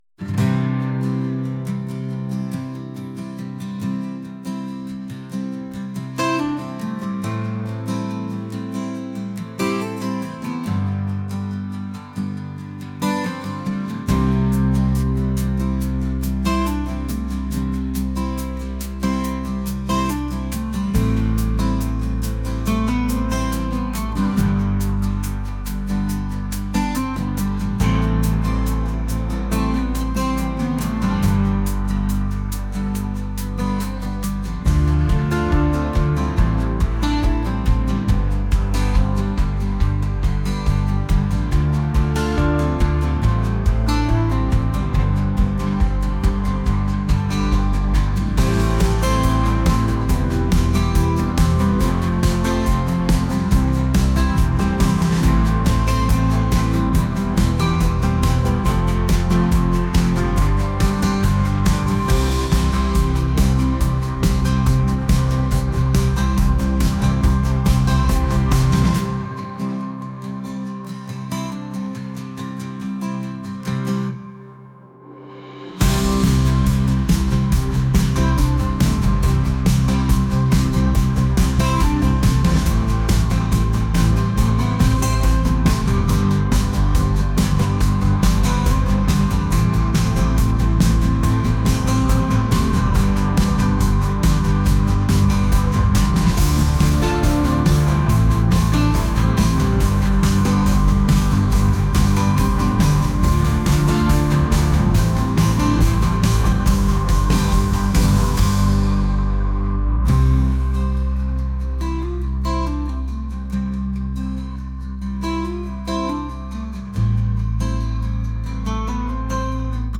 acoustic | indie | folk